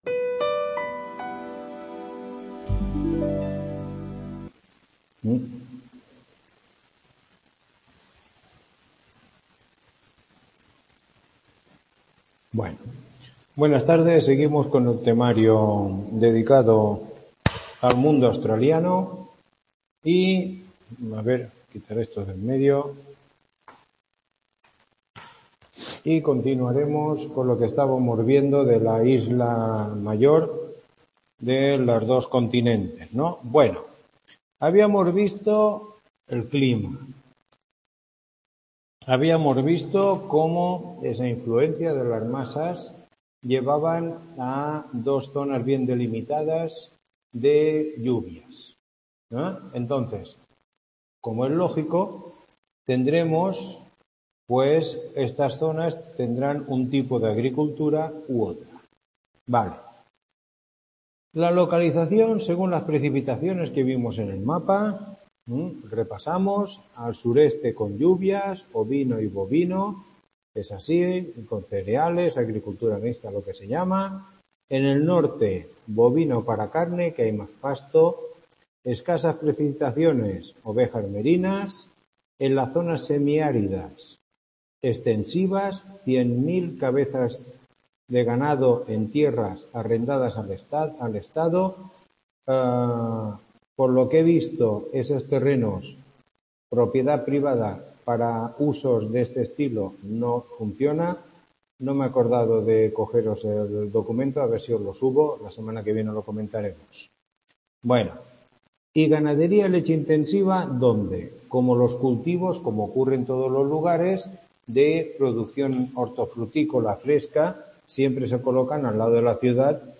Tutoría 09